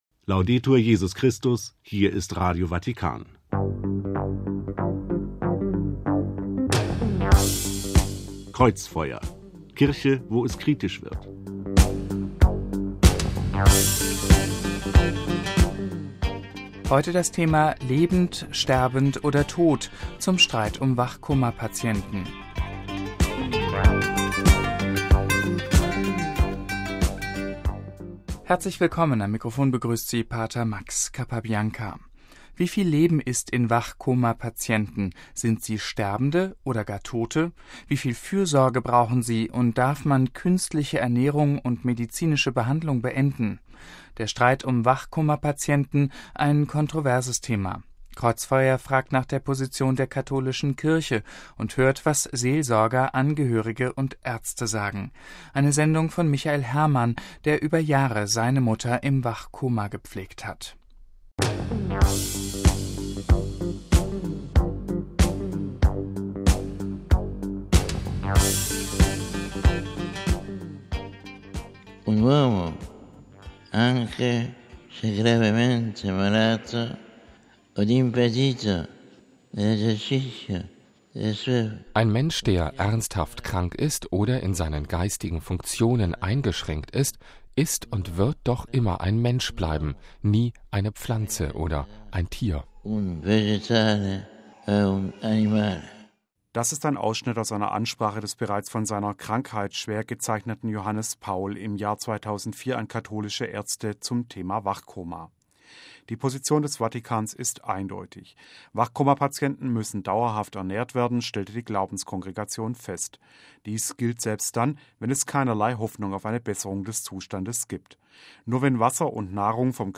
Kreuzfeuer fragt nach der Position der katholischen Kirche und hört, was Seelsorger, Angehörige und Ärzte sagen.
Das ist ein Ausschnitt aus einer Ansprache des bereits von seiner Krankheit schwer gezeichneten Johannes Paul im Jahr 2004 an katholische Ärzte zum Thema Wachkoma.